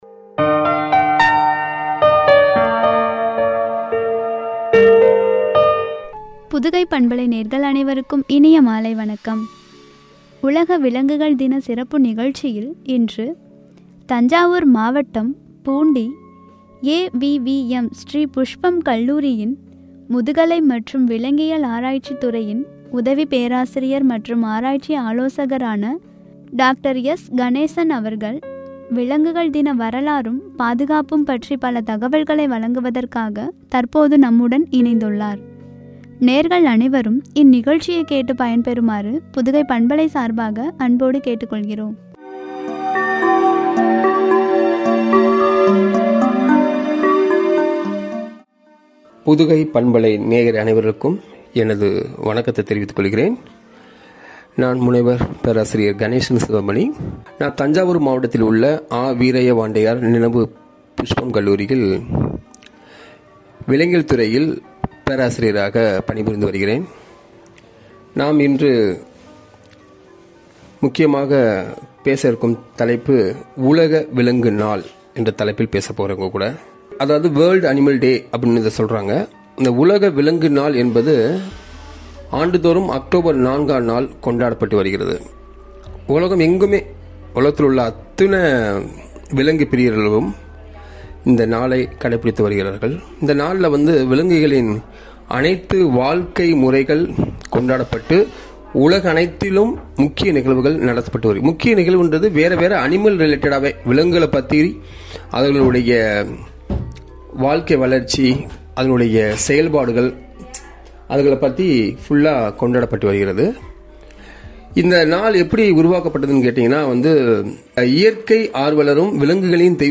பாதுகாப்பும்” என்ற தலைப்பில் வழங்கிய உரையாடல்.